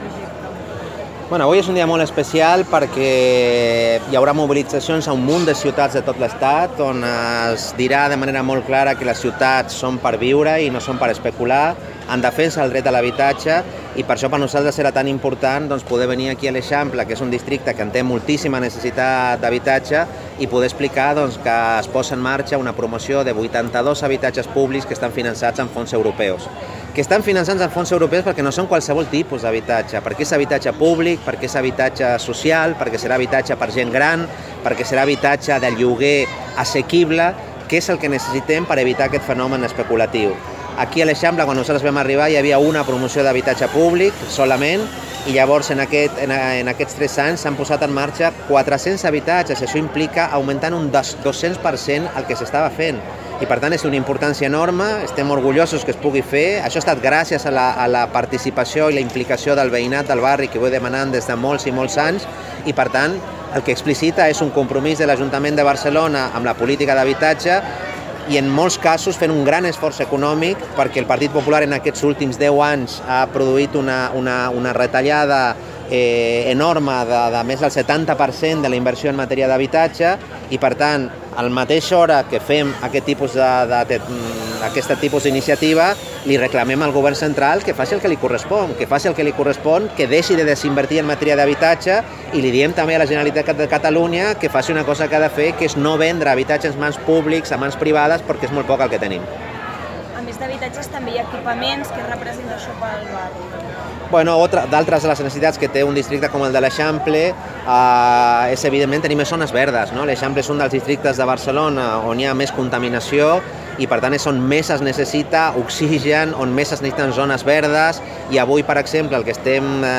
Declaracions Gerardo Pisarello (mp3)